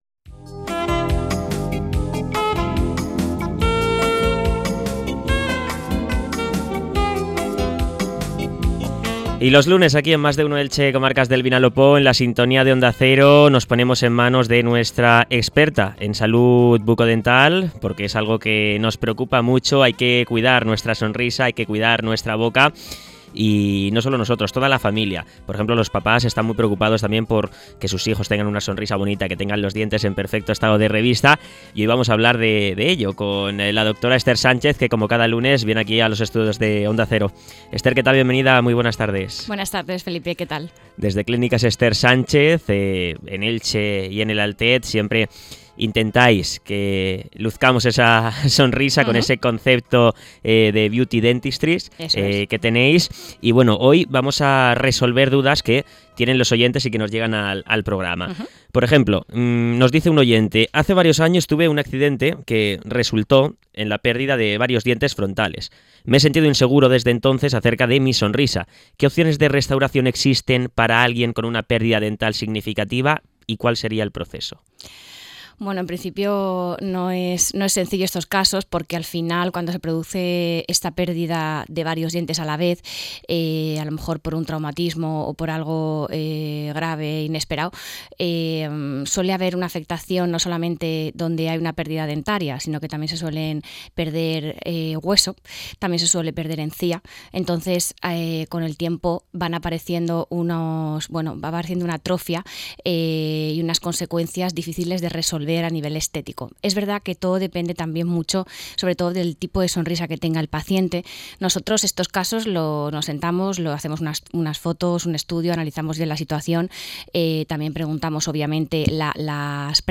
Audioblog